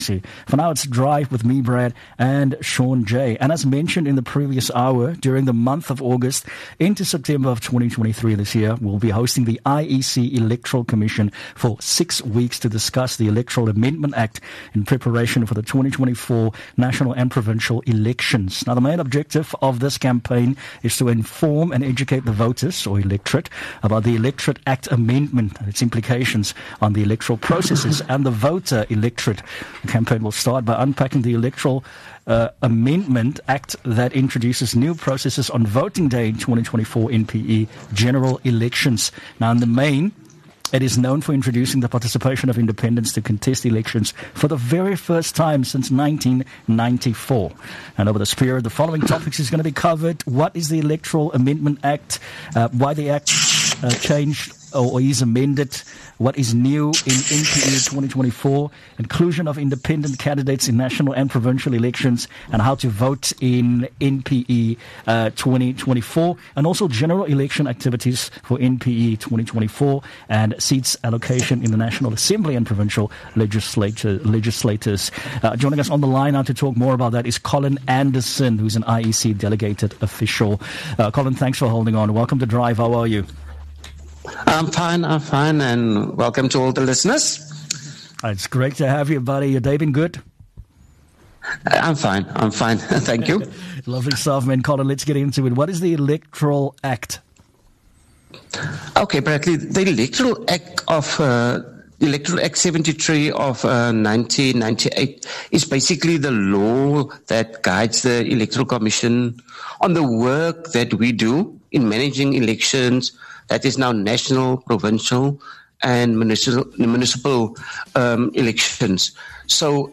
Community Radio Programme